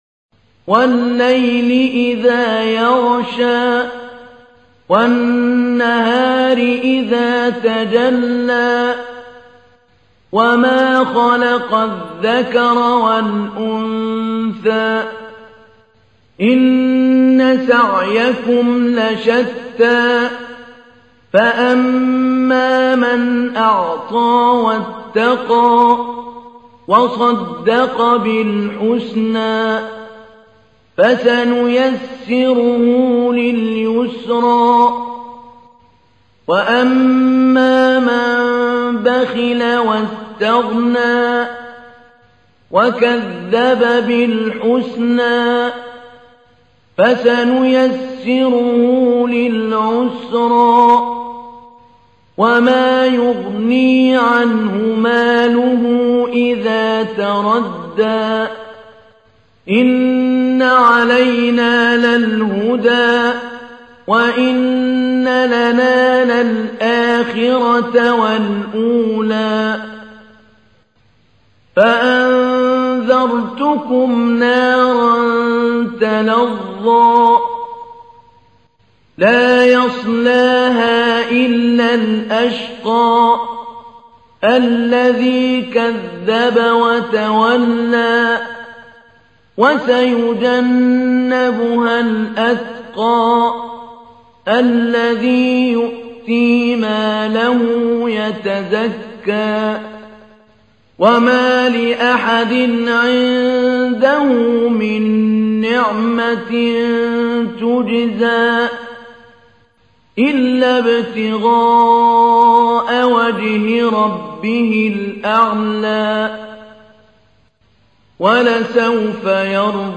تحميل : 92. سورة الليل / القارئ محمود علي البنا / القرآن الكريم / موقع يا حسين